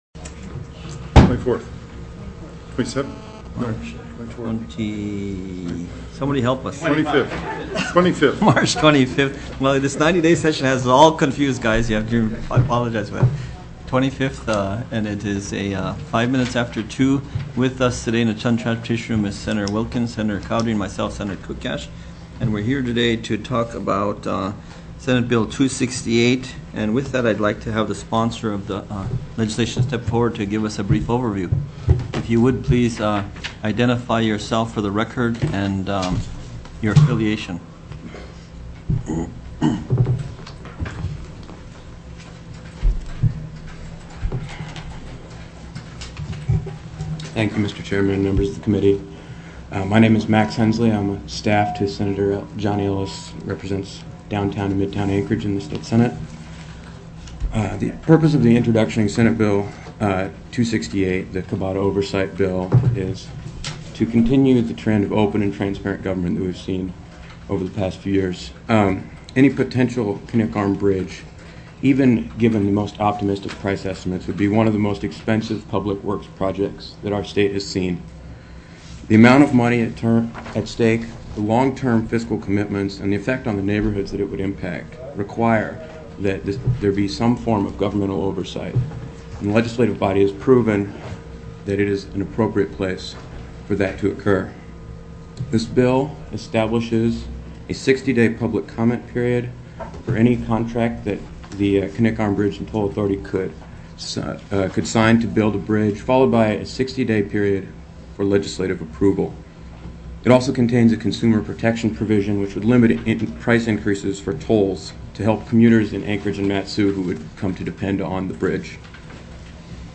03/25/2008 02:00 PM Senate TRANSPORTATION